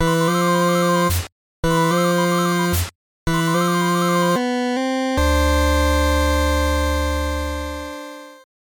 A thing with two pulse waves, a triangle and a noise generator, kind of in the key of F, with the triangle arping in E miner. Eventually resolves to D something.